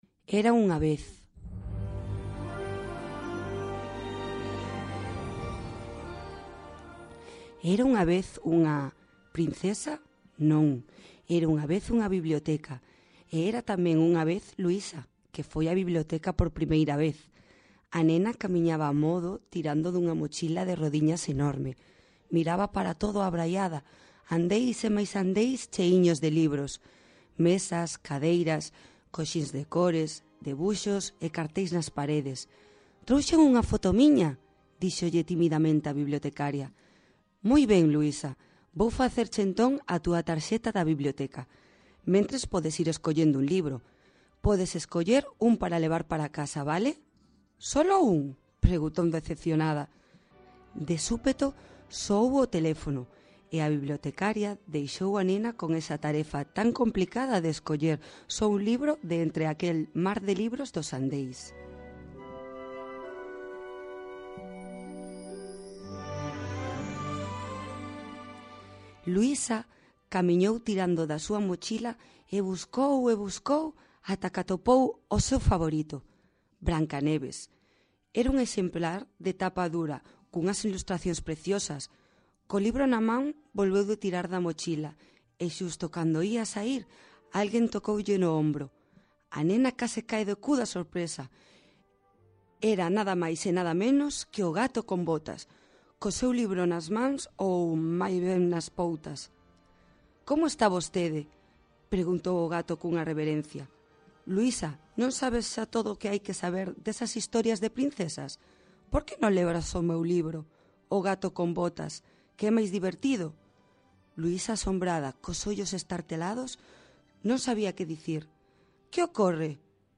le o pregón do Día do Libro Infantil no programa A Estación de Radio Redondela dentro da emisión literaria